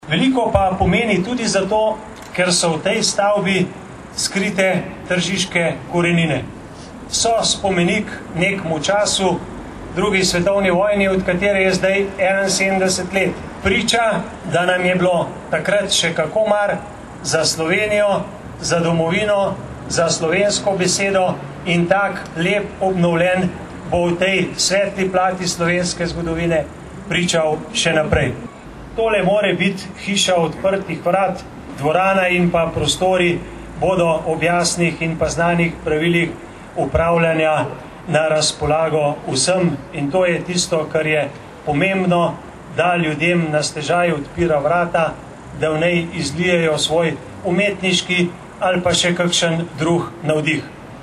Da obnovljen objekt pridnim in iznajdljivim omogoča marsikaj, je na slavnostnem odprtju paviljona povedal župan Občine Tržič mag. Borut Sajovic.
47429_izjavazupanaobcinetrzicmag.borutasajovica_odprtjepaviljonanob.mp3